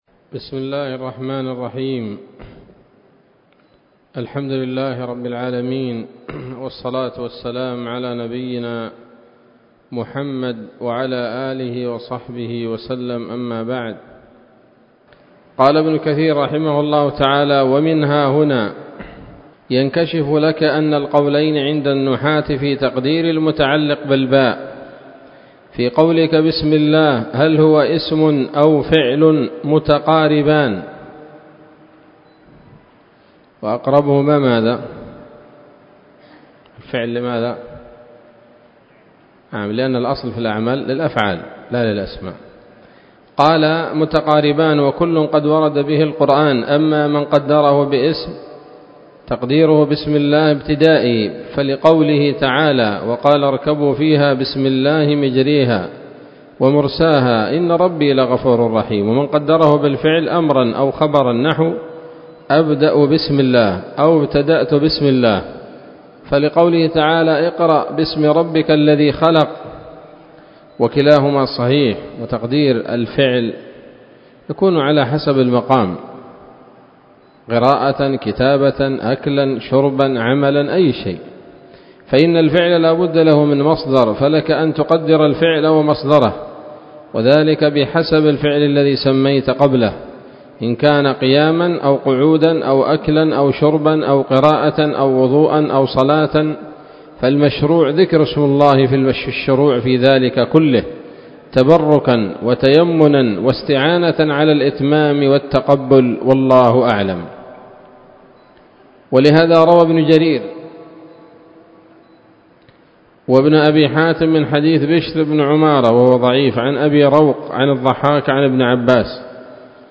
الدرس الحادي عشر من سورة الفاتحة من تفسير ابن كثير رحمه الله تعالى